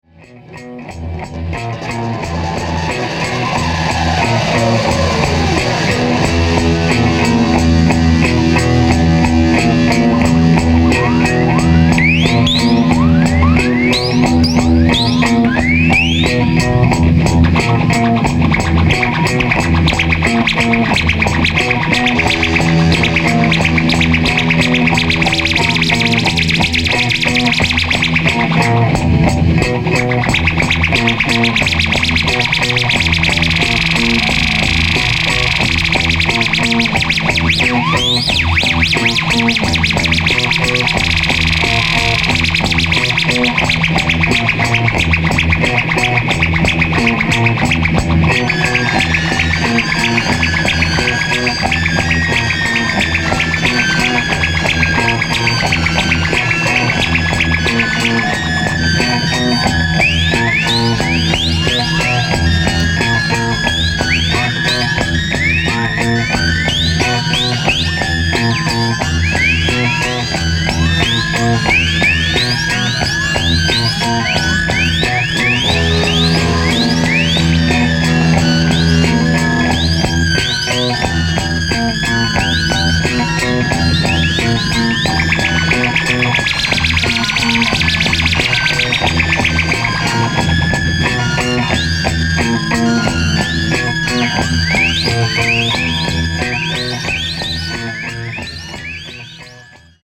キーワード：ミニマル　即興　地球外　コズミック　サイケ